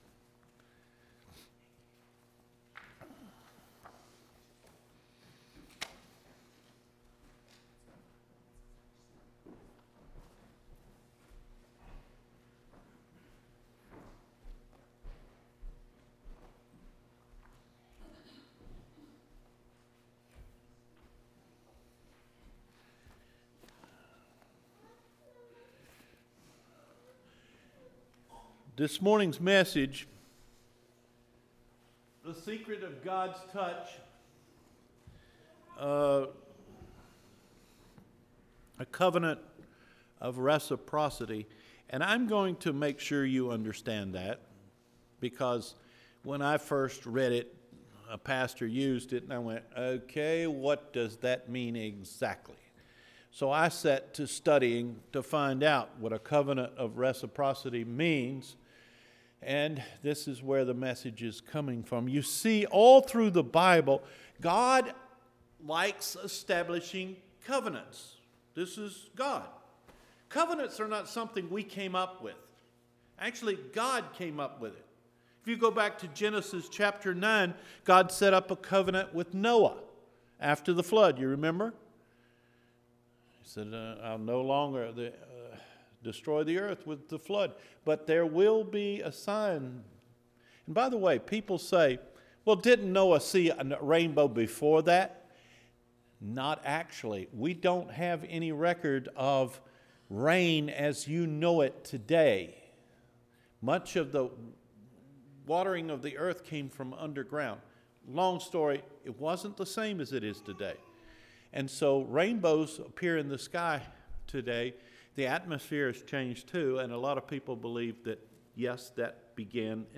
THE SECRET OF GOD’s TOUCH – JUNE 23 SERMON